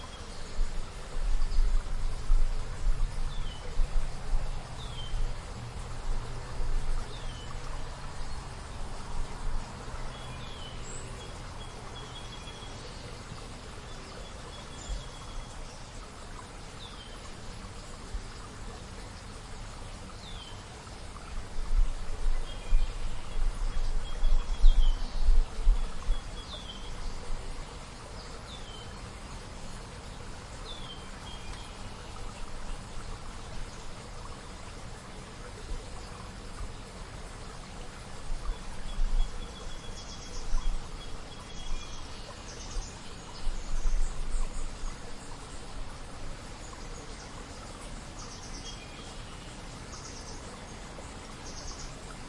湖区附近的鸟类
描述：在加利福尼亚北部的一个湖附近的环境记录。湖中有一些有趣的鸟类。
Tag: 周围环境 在户外 环境 自然